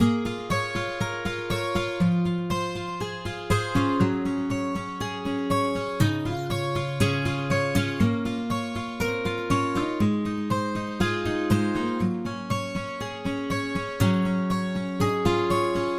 西班牙吉他第77号
标签： 120 bpm RnB Loops Guitar Acoustic Loops 2.69 MB wav Key : E Magix Music Maker
声道立体声